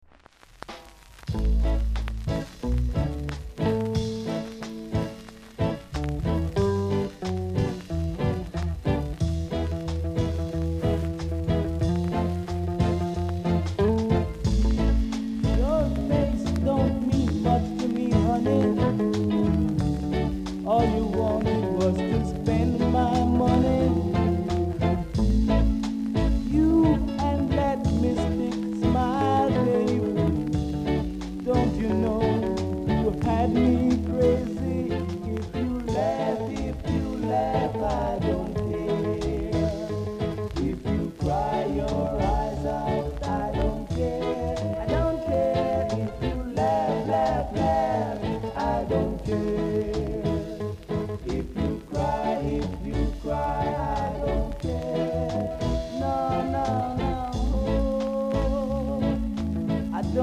※全体的に薄くジリジリします。ほかチリ、パチノイズが単発で少しあります。盤は細かい薄い擦り傷、小傷があります。
コメント BIG ROCKSTEADY!!